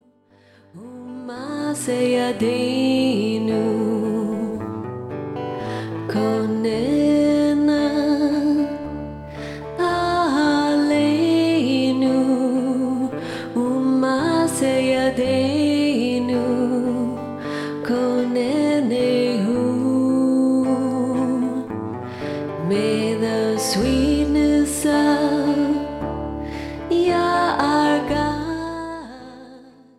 powerful Hebraic chants